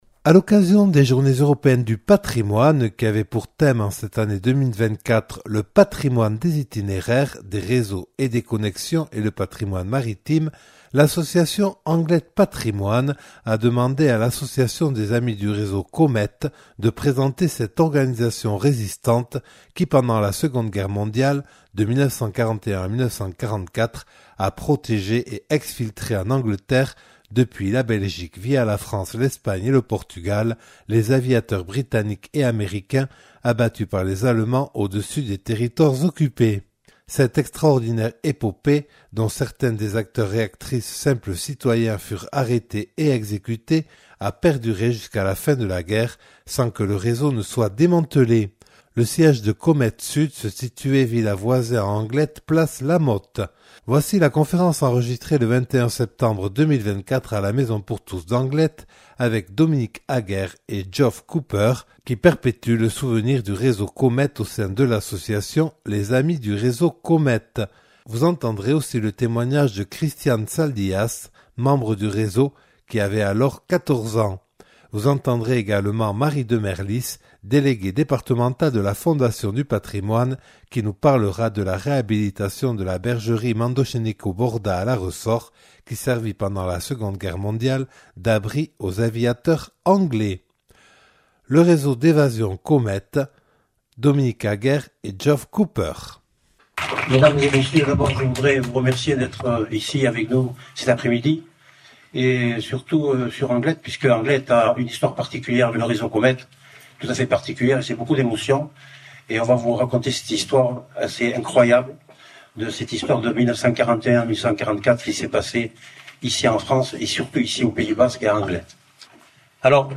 Conférence enregistrée le samedi 21 septembre 2024 à la Maison pour tous d’Anglet à l’initiative de l’association Anglet Patrimoines.